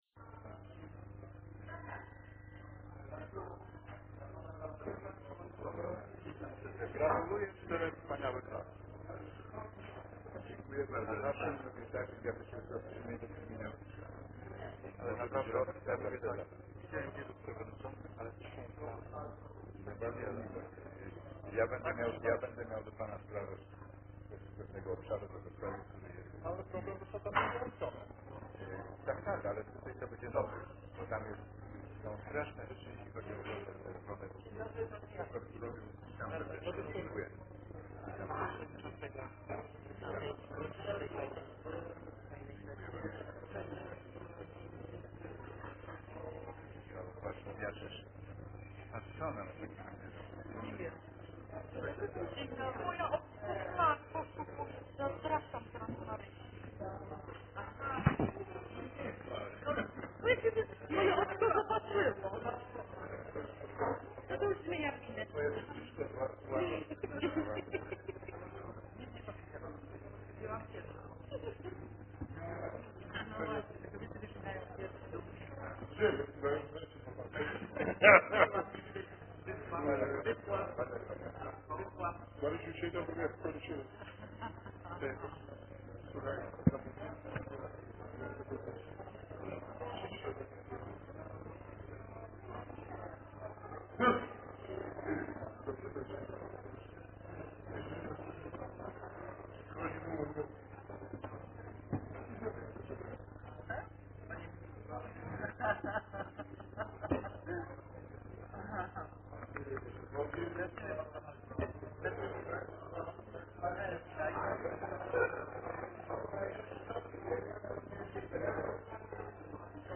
Protokół nr LXV sesji RMW z dnia 6 listopada 2014 r. - Protokoły z sesji - Biuletyn Informacji Publicznej Urzędu Miejskiego Wrocławia